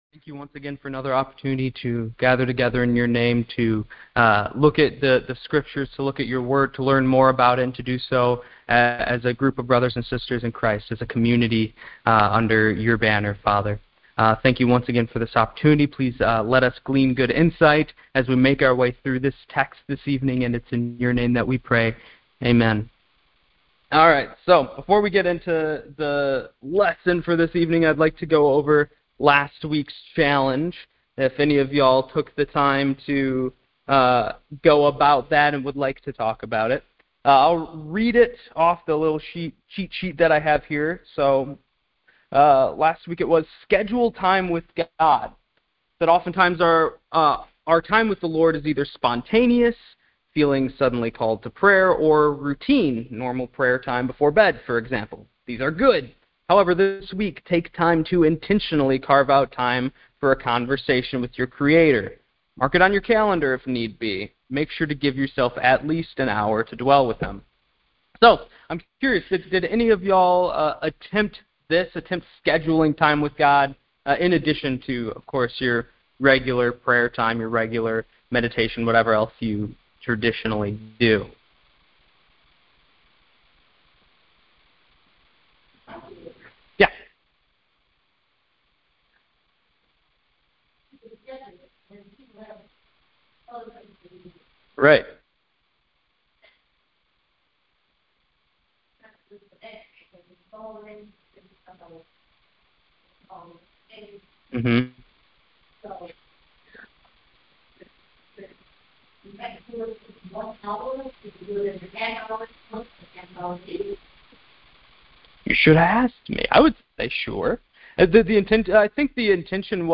Bible Study 8.13.25